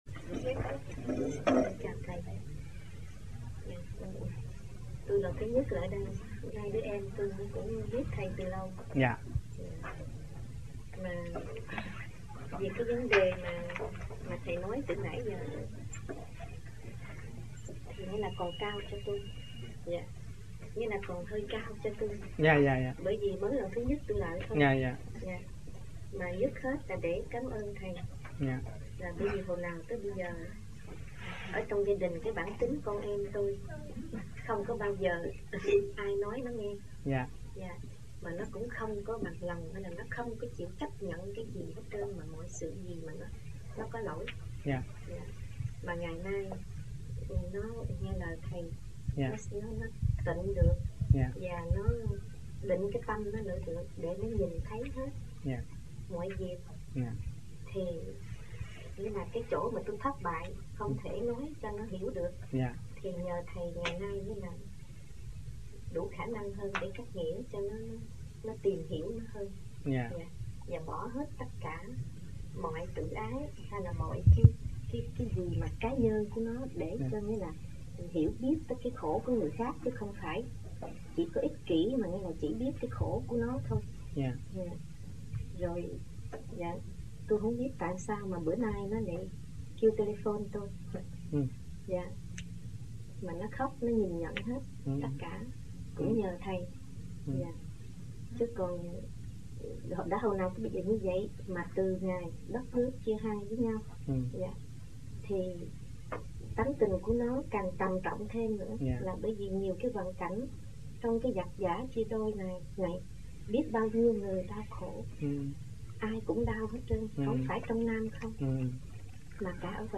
1980-11-11 - NANTERRE - THUYẾT PHÁP 03